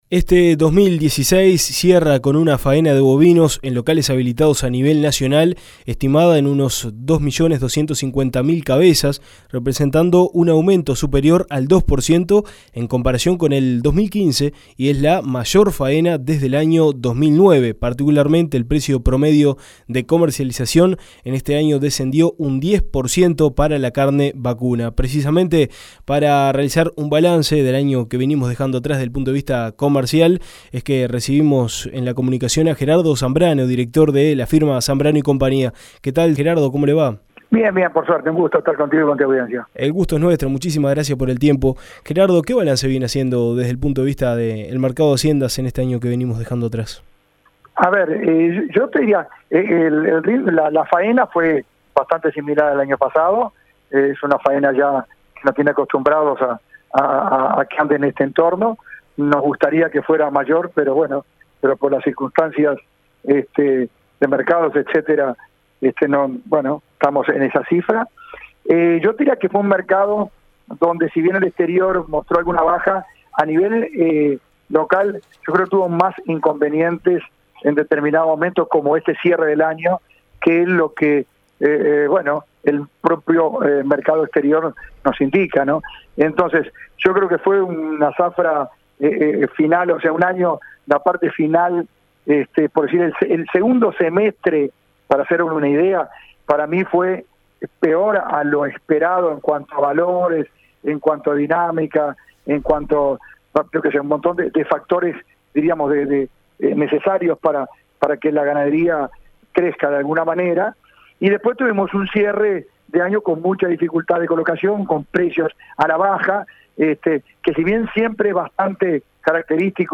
En 2016 el volumen exportado de carne bovina fue el mayor de la última década superando las 400.000 toneladas peso canal. El ingreso medio fue de 3.400 dólares por tonelada peso canal, lo que representa una caída del 10% en los valores respecto al 2015 según información del INAC. En entrevista con Dinámica Rural